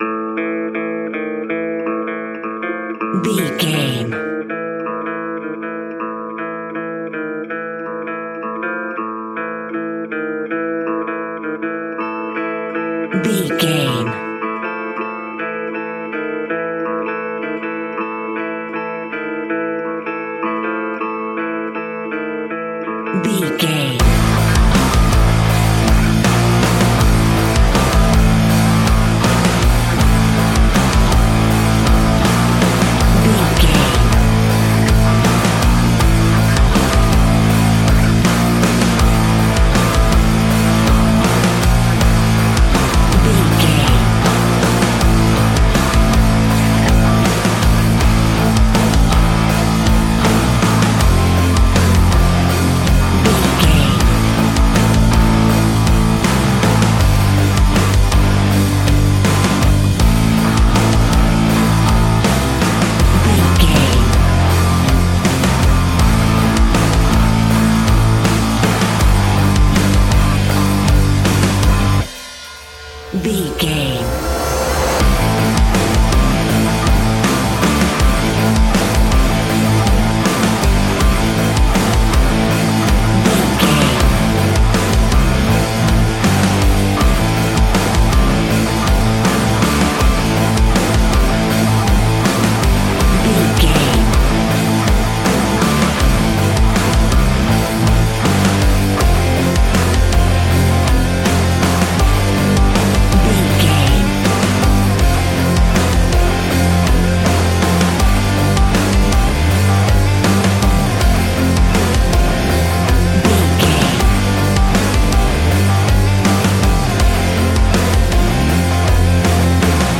Ionian/Major
A♭
hard rock
guitars
heavy metal
instrumentals